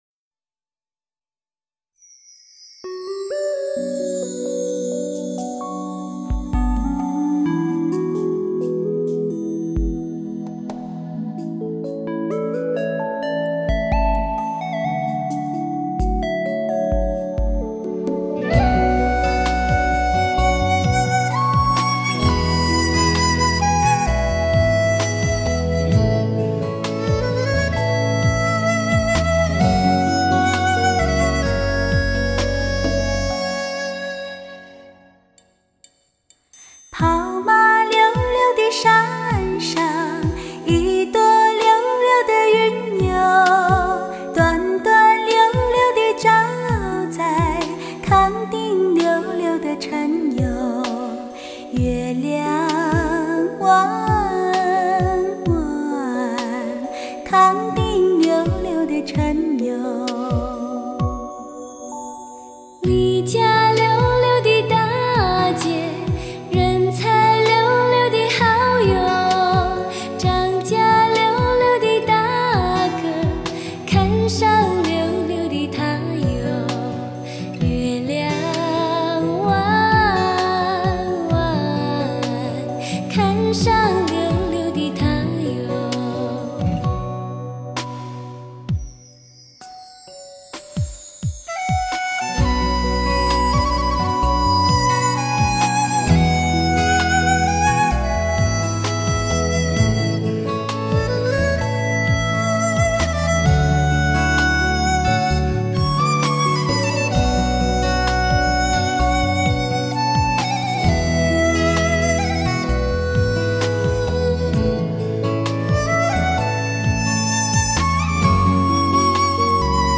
甜蜜如软糖的声音，正甜蜜地为您吟唱出经典与时尚的优美旋律。